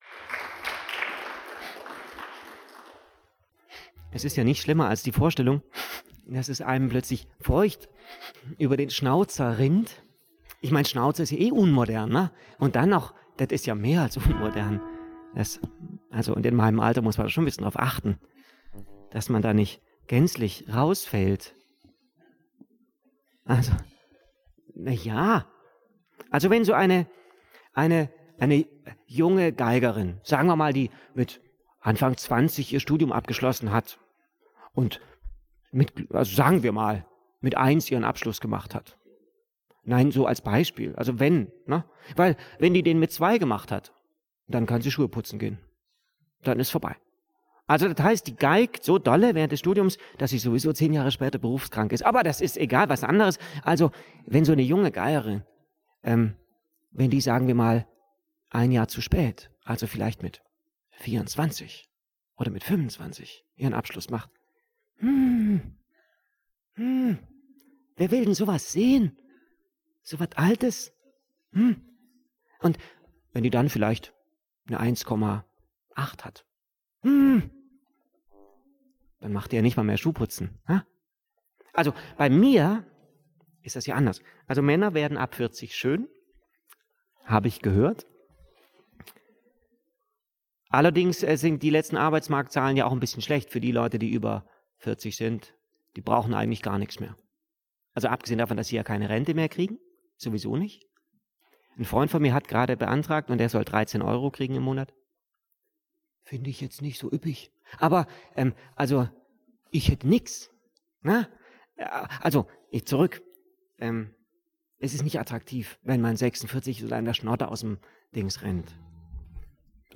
live. 2:02min